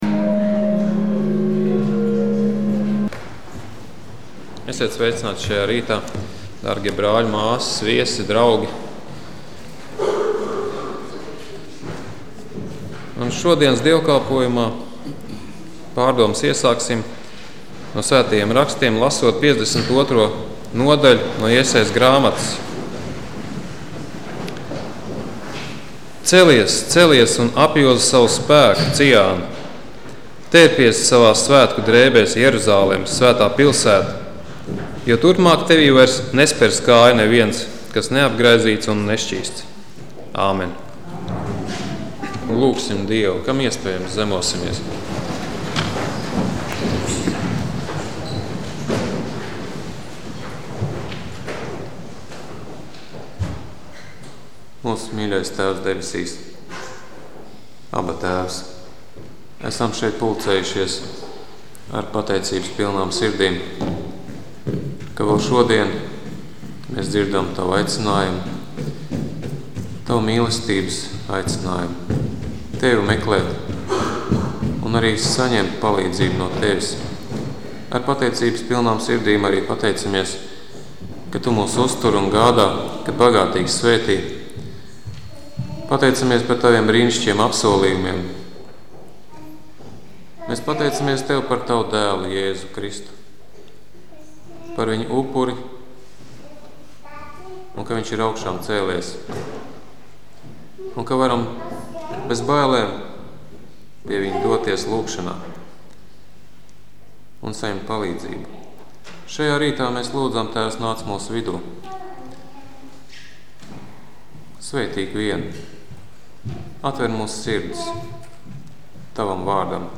Lūgšanas nedēļas lasijumi dievkalpojumā : Klausīties